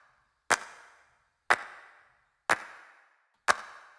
31 Clap.wav